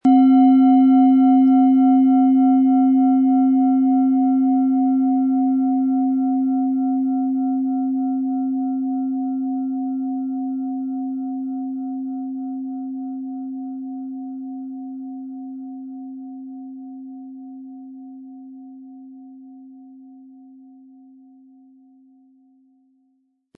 Planetenschale® antik Wohlige Energie im Brustbereich & Umsetzen und in die Tat kommen mit DNA-Ton & Jupiter, Ø 13,5 cm, 320-400 Gramm inkl. Klöppel
Diese Schale begleitet Sie warm, still und präsent.
Sie trägt die DNA-Frequenz in sich und verbindet sie mit der Weite von Jupiter. So entsteht ein Klang, der Raum gibt, ordnet und freundlich ausrichtet.
Die DNA-Frequenz (528 Hz):
Der mittlere Ton: Jupiter
Im Sound-Player - Jetzt reinhören können Sie den Original-Ton genau dieser Schale anhören.
Durch die traditionsreiche Fertigung hat die Schale vielmehr diesen kraftvollen Ton und das tiefe, innere Berühren der traditionellen Handarbeit